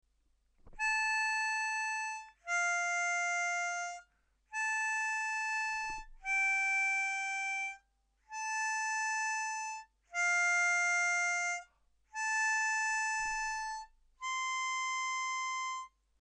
That means two holes….at the same time.